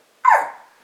Aboiement-chien-Scott-x1-DSC_0074.mp3